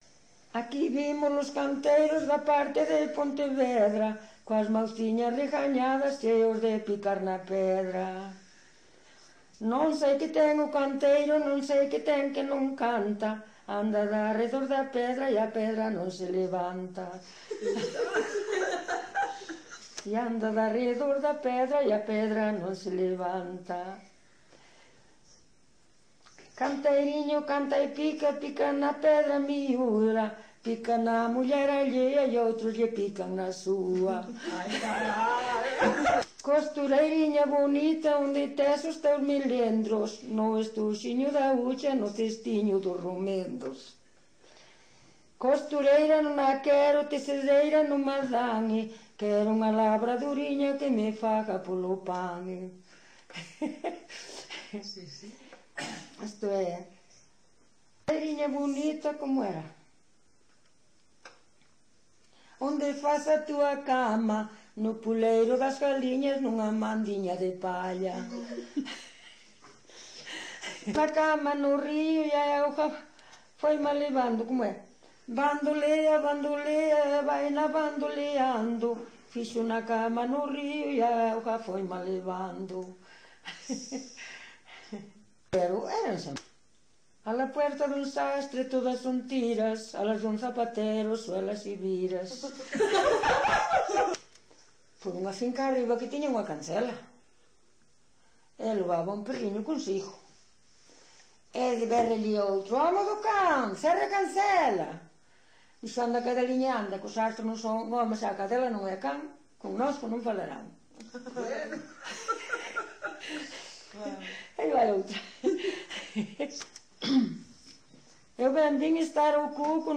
Coplas.
Tipo de rexistro: Musical
Lugar de compilación: Vila de Cruces - Sabrexo (Santa María) - Sabrexo
Soporte orixinal: Casete
Instrumentación: Voz
Instrumentos: Voz feminina